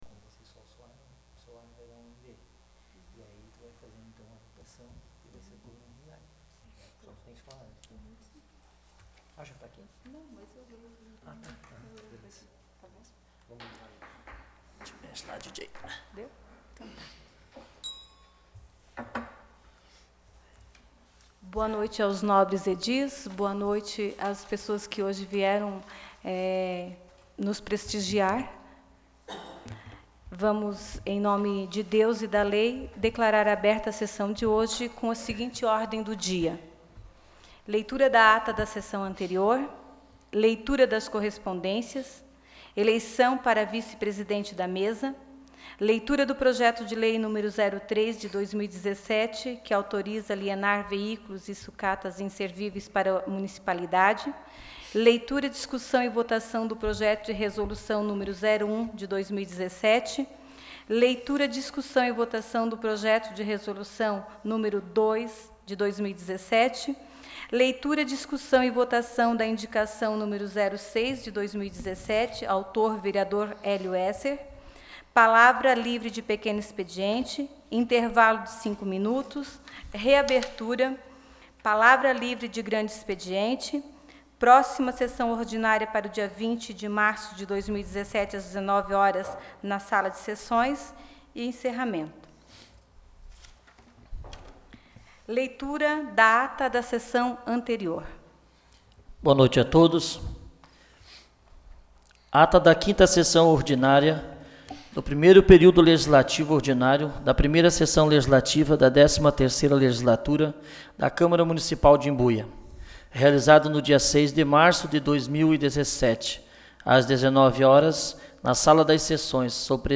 Áudio da Sessão Ordinária de 13 de março de 2017.